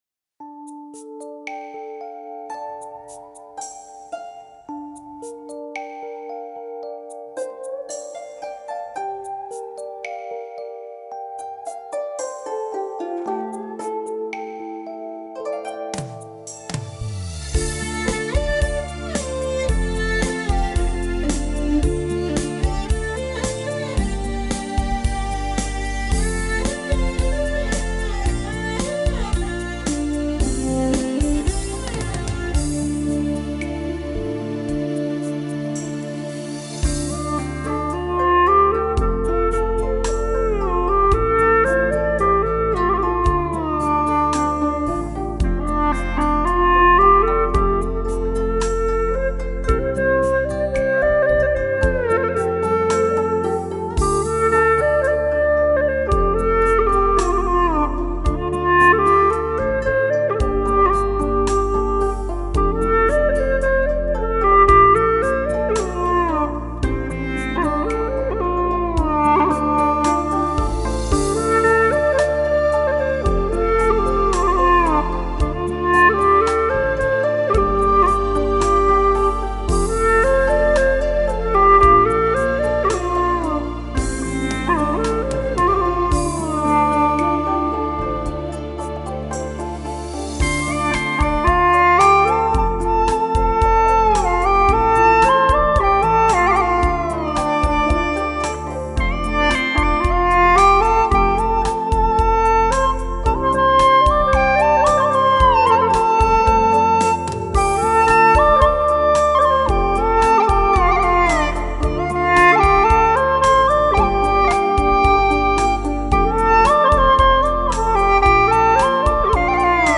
调式 : F 曲类 : 流行
【大小F调】